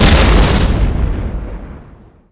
auto_alt_explode.wav